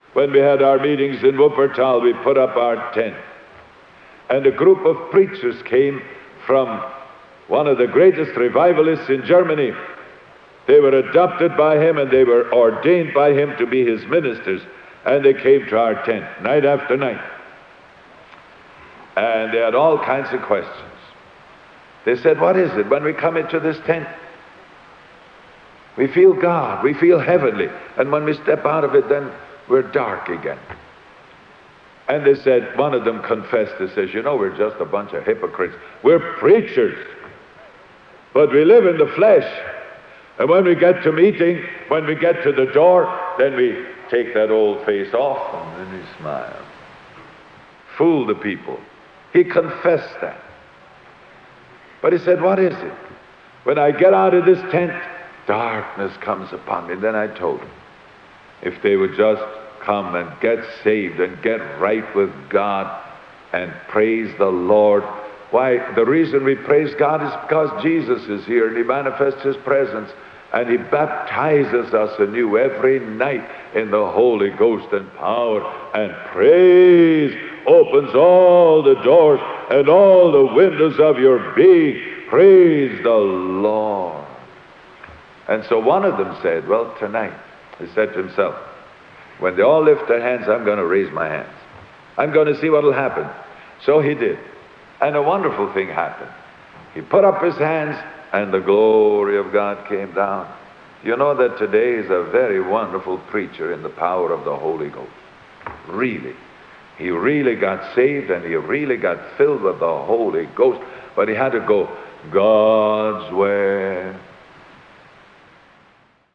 Audio Quality: Fair
Original tape marked (53).  For side B, even light hiss reduction introduced too many artifacts.  We resampled to speed up to 98% of the original duration.  There were two separate hum tones, so we used DeHummer -25dB, 8 filter, 57.45Hz, as well as -28dB, 4 filter, 60.45Hz; and compression.  Perhaps extra hiss removal should have been attempted in the vegas track effects.  I wonder if some of that extra noise is rain.
There are numerous tape dropouts; only a dozen or so were removed.